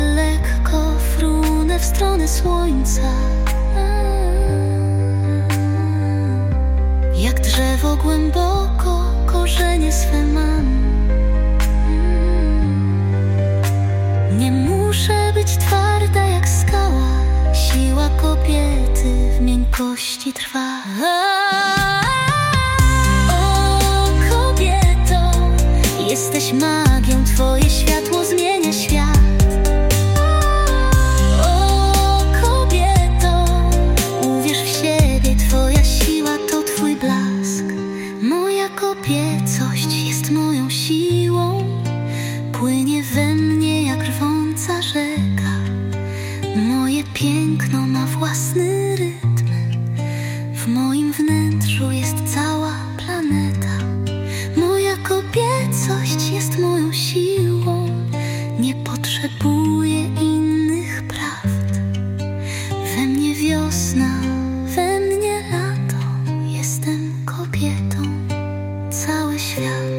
to wzruszający, poetycki utwór